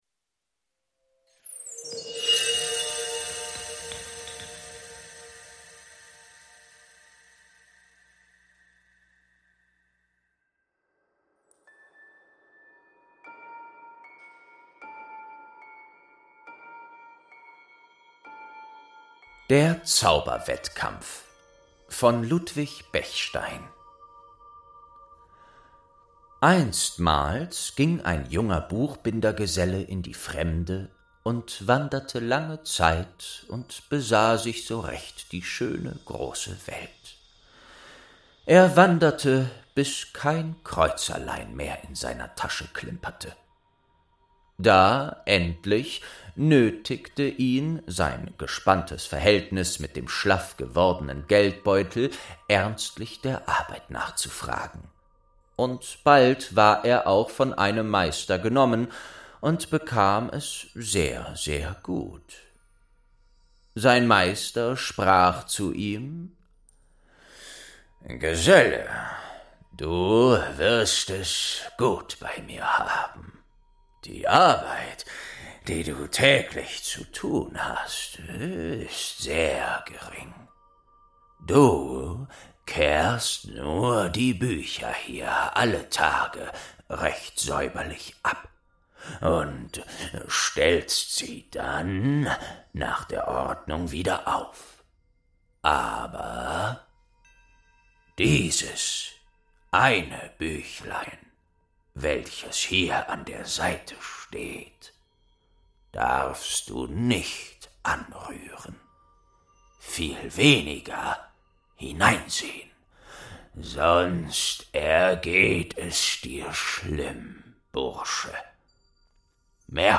4. Der Zauberwettkampf | Staffel 2 ~ Märchen aus der verschollenen Bibliothek - Ein Hörspiel Podcast